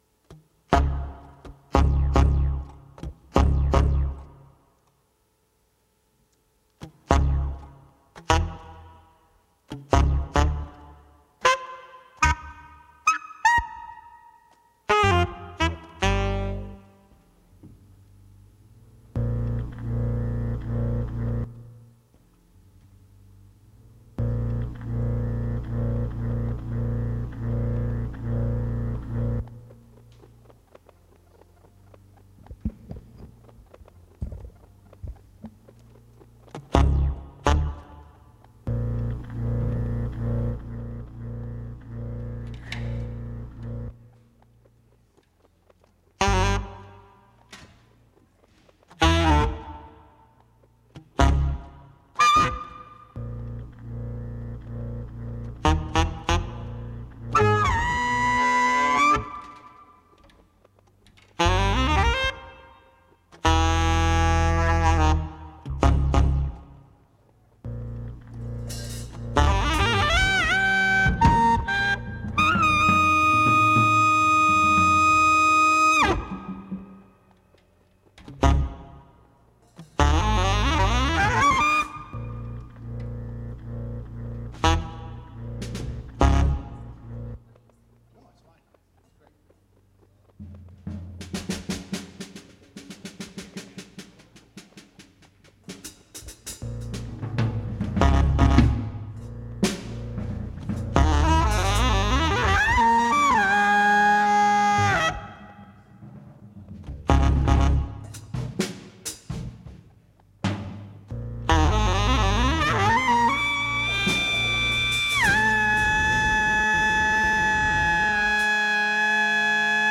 drums
alto saxophone
Stereo (Pro Tools)